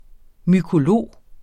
Udtale [ mykoˈloˀ ]